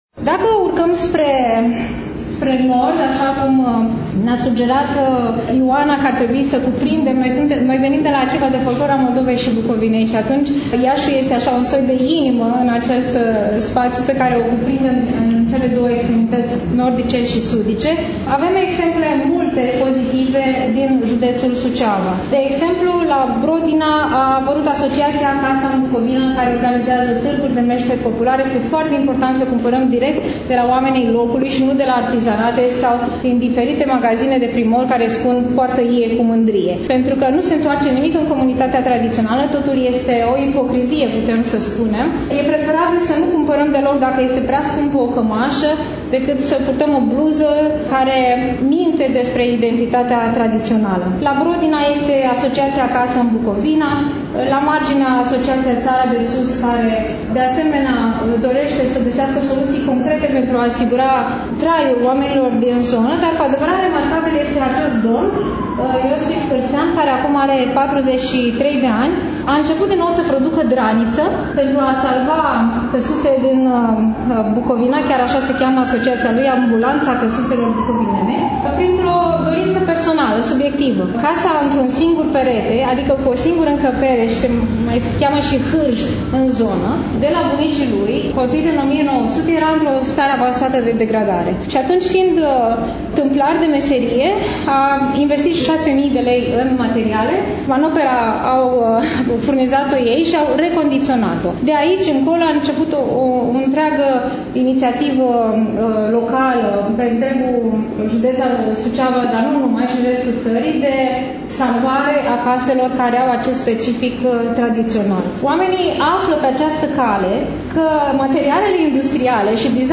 Astăzi, după cum bine știți, relatăm de la expoziţia de carte „Satul românesc”, manifestare culturală desfășurată, nu demult, la Iași, în incinta Bibliotecii Centrale Universitare „Mihai Eminescu”.